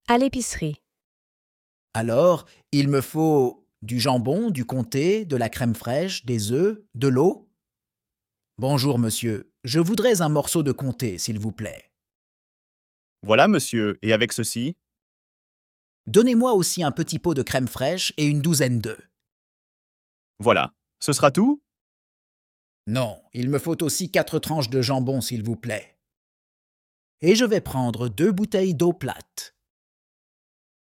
Dialogue en français – À l’épicerie (Niveau A2)